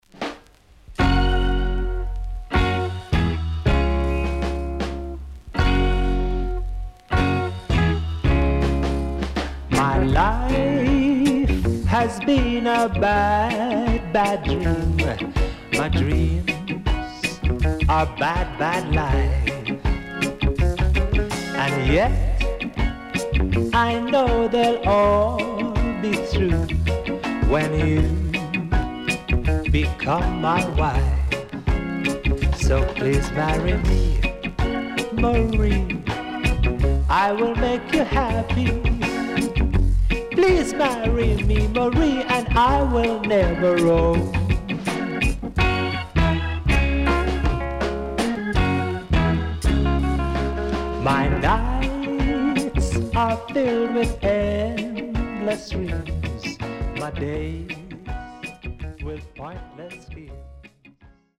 HOME > REGGAE / ROOTS  >  RECOMMEND 70's
SIDE A:少しノイズ入りますが良好です。